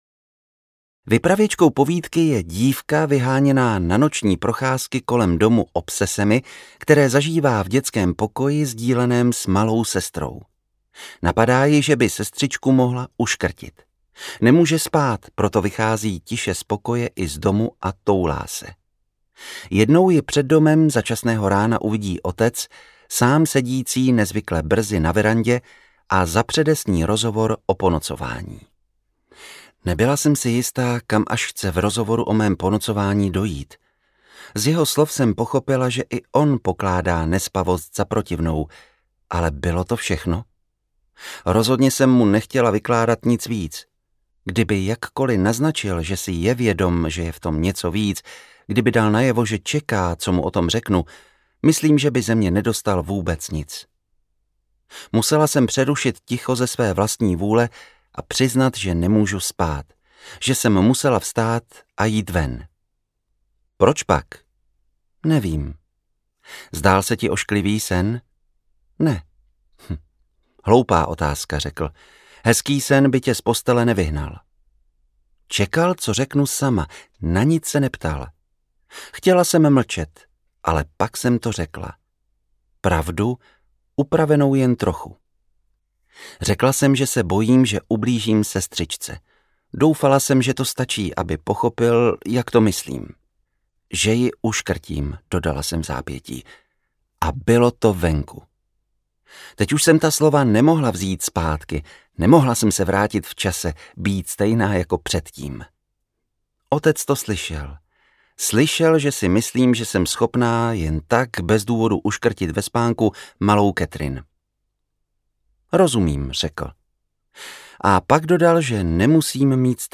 Jak se stát dobrým psychoterapeutem audiokniha
Ukázka z knihy
| Vyrobilo studio Soundguru.